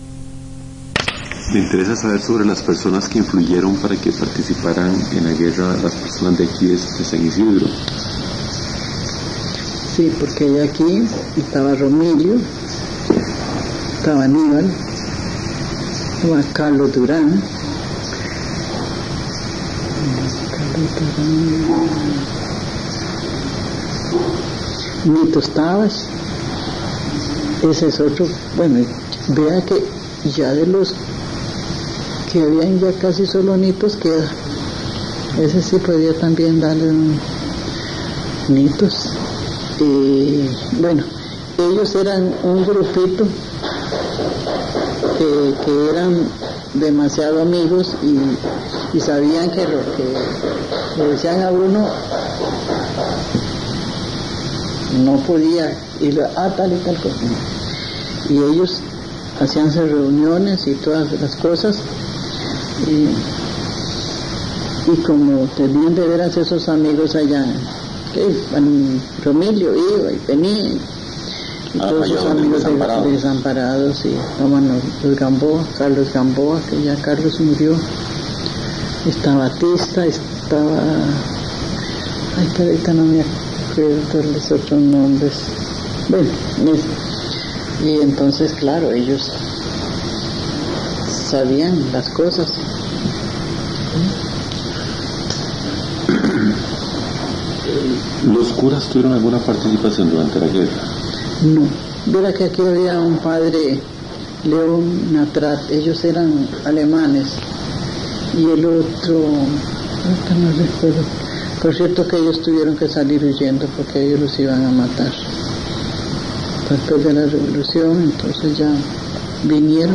Entrevista
Notas: Casete de audio y digital